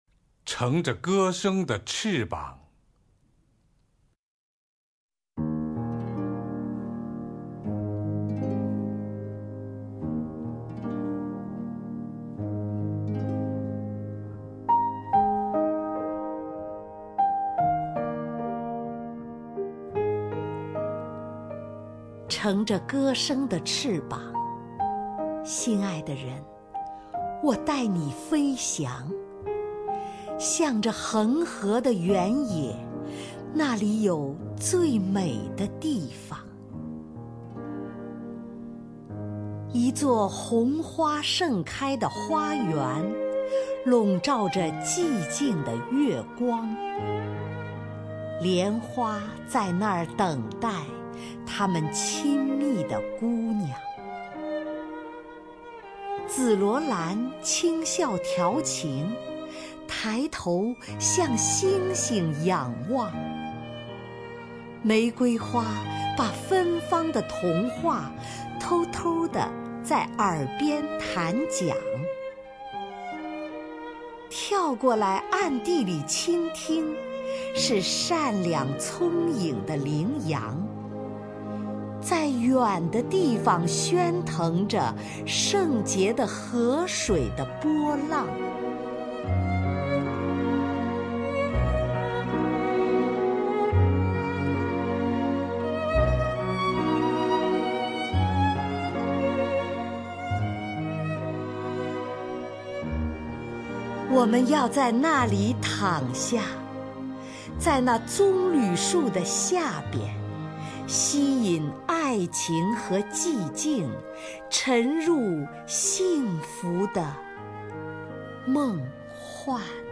首页 视听 名家朗诵欣赏 丁建华
丁建华朗诵：《乘着歌声的翅膀》(（英）海因里希·海涅，译者：冯至)